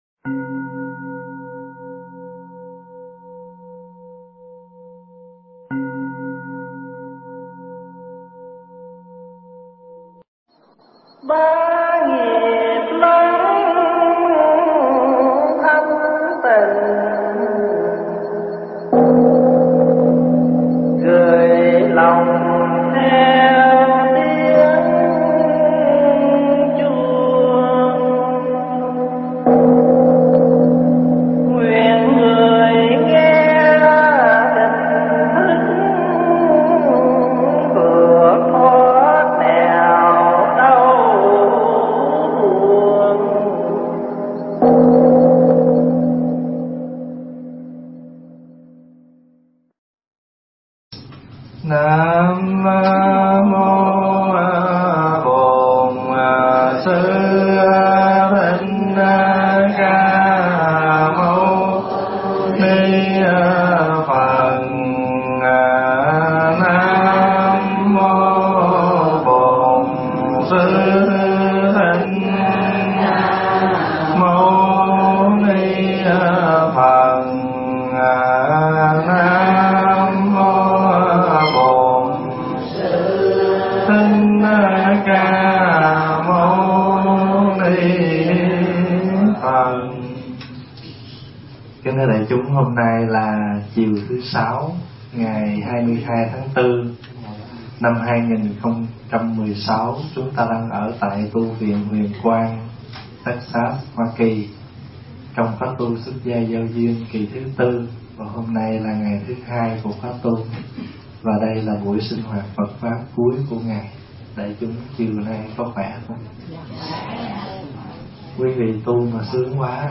Nghe Mp3 thuyết pháp Vượt Bộc Lưu 2